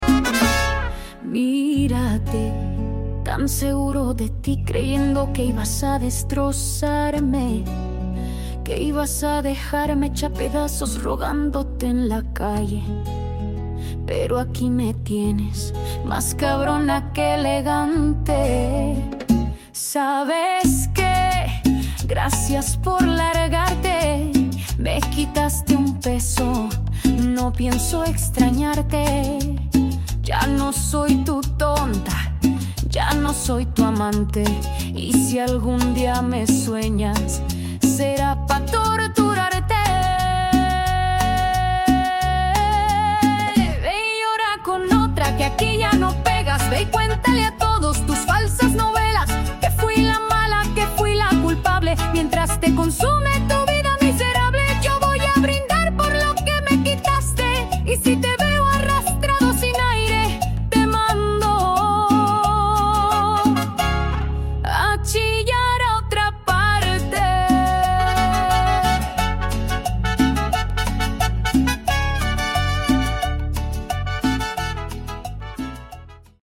Cumbia Argentina
Musica regional popular Mexicana argentina